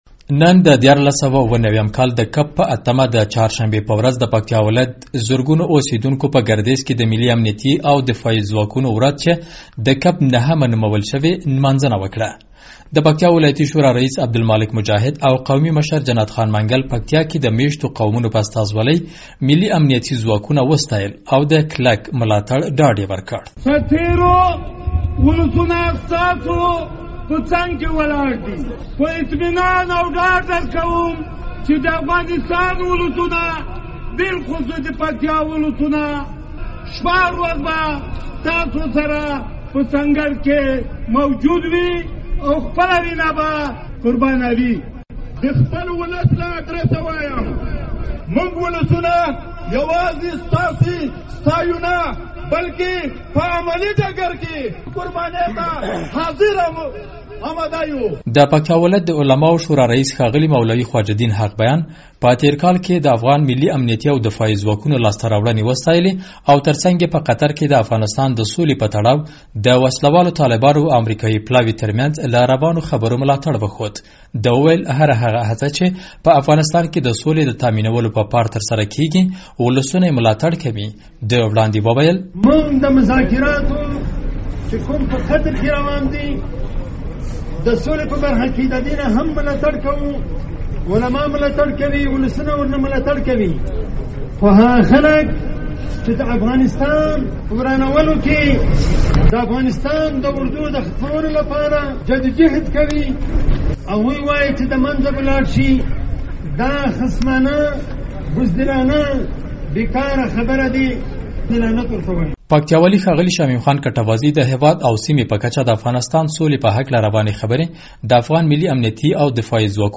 د ۱۳۹۷ کال د کب په ۸مه د چهارشنبې په ورځ د پکتیا ولایت زرګونه اوسېدونکو په ګردېز کې د ملي امنیتي او دفاعي ځواکونو ورځ ولمانځله.